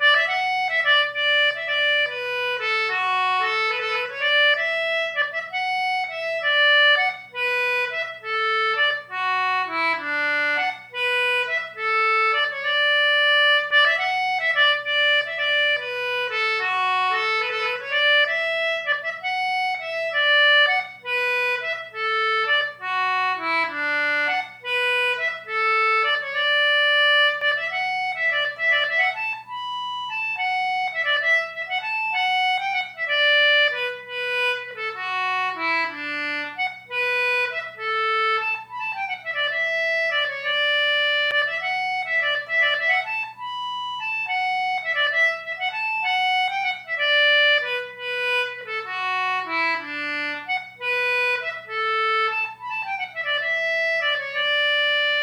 Most Celtic tunes were written for a specific instrument, such as the harp, flute, whistle, etc. The audio clips on this web site were played using English concertinas.